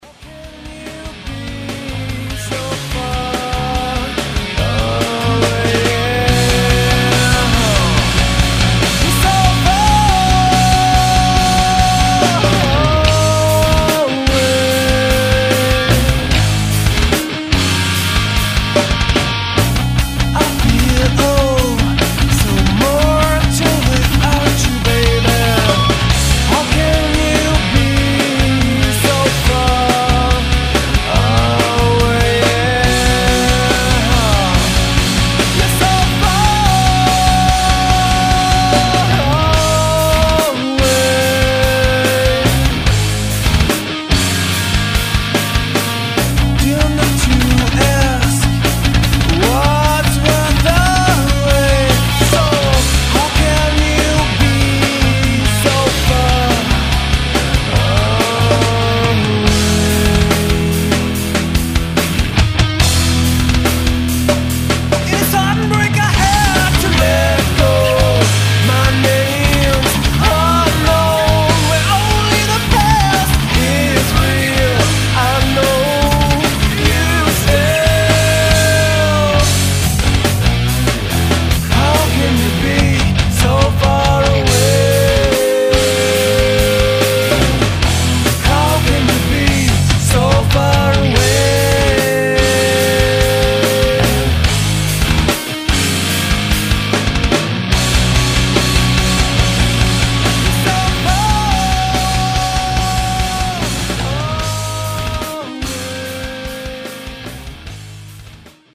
Fette Strophe.